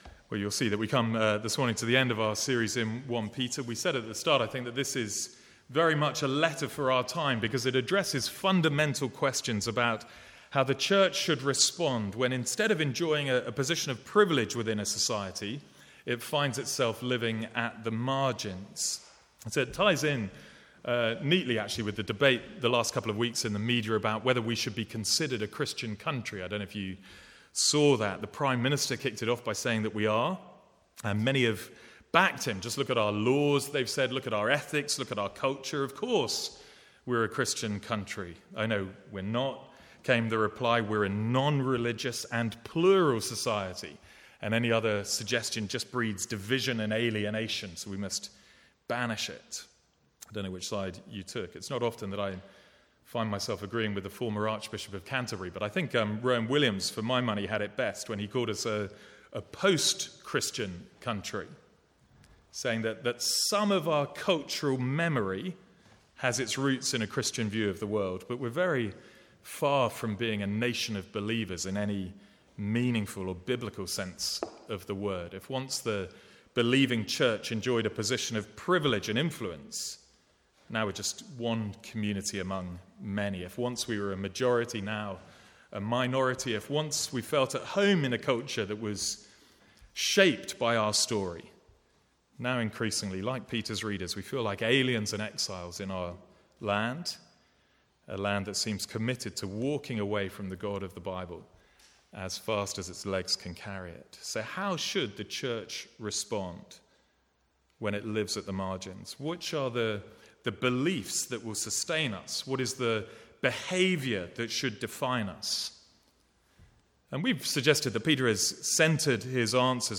From the Sunday morning series in 1 Peter.